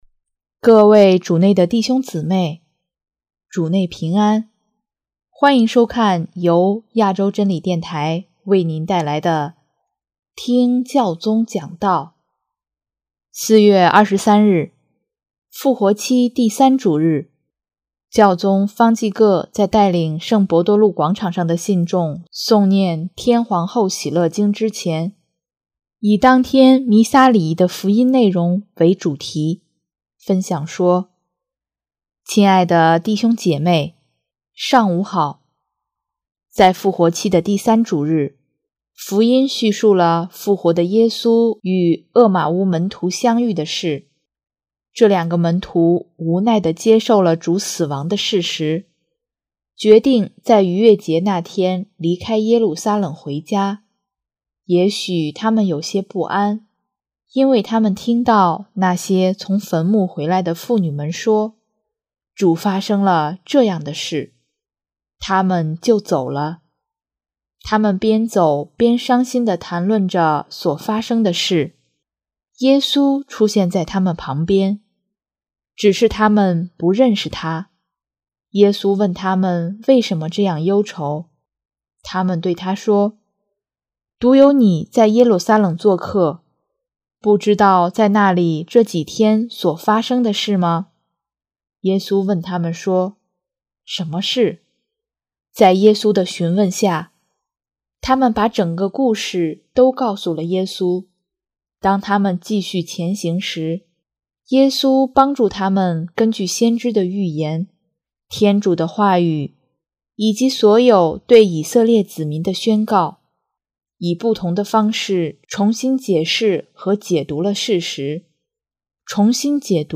4月23日，复活期第三主日，教宗方济各在带领圣伯多禄广场上的信众诵念《天皇后喜乐经》之前，以当天弥撒礼仪的福音内容为主题，分享说：